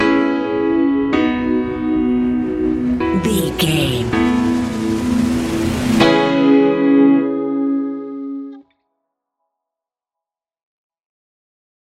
Ionian/Major
ambient
electronic
chill out
downtempo
synth
pads